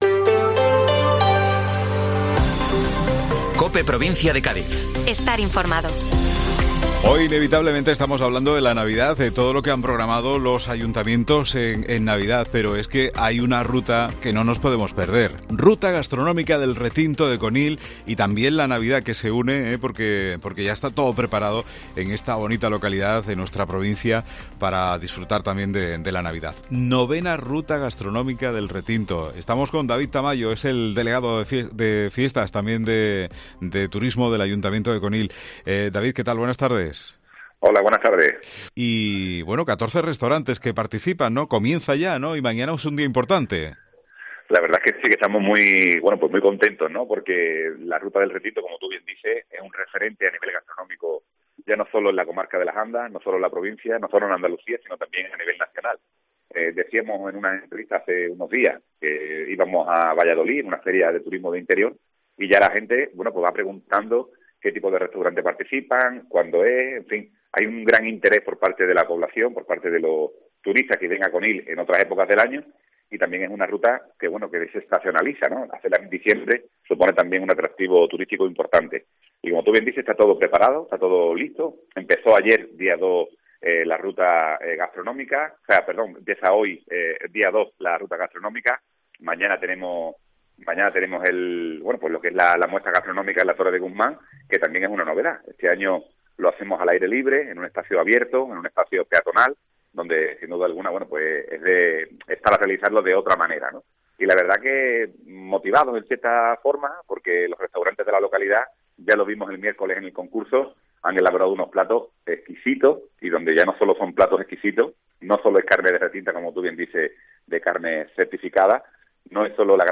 David Tamayo, Deleg de Turismo del Ayto de Conil - Arranca la Ruta Gastronómica del Retinto de Conil